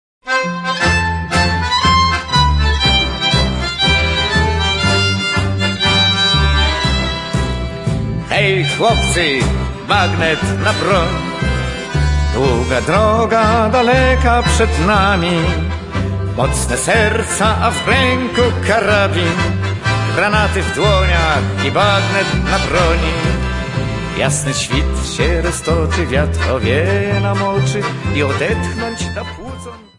Polish songs